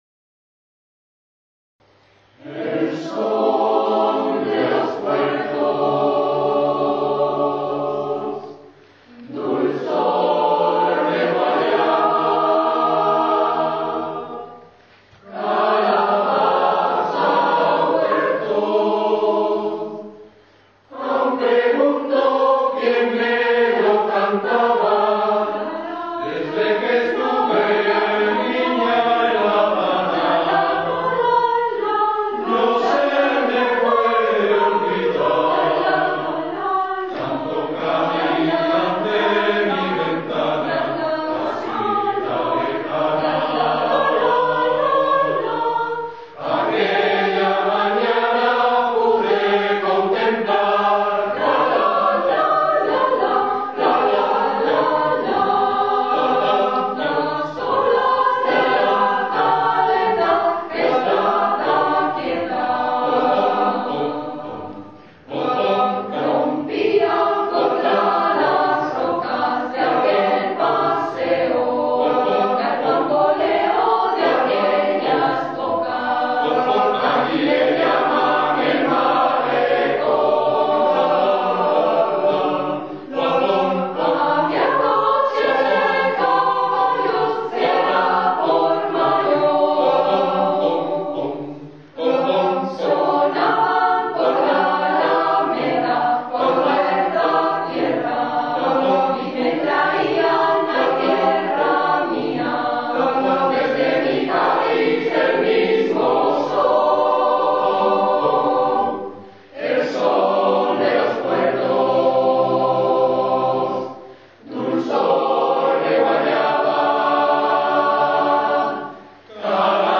Coro de padres «La Palmera»